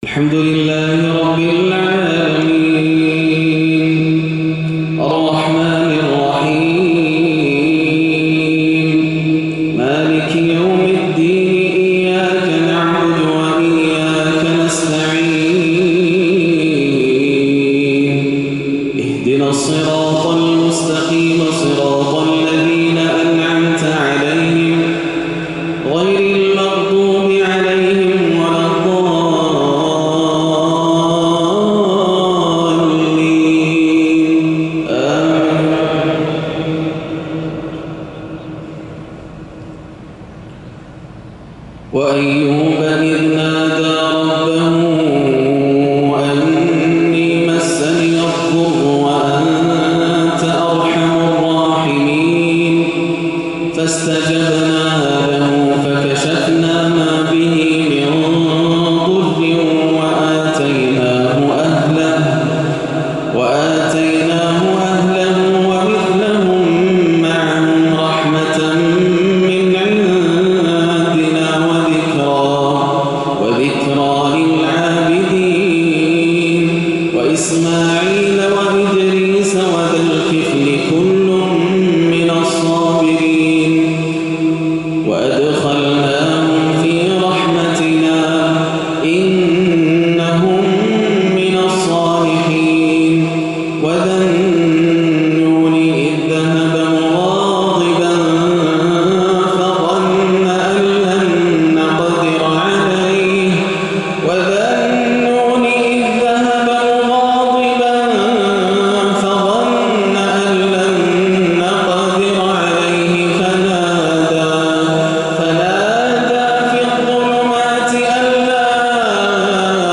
عشاء الثلاثاء 7-1-1437هـ من سورة الأنبياء 83-106 > عام 1437 > الفروض - تلاوات ياسر الدوسري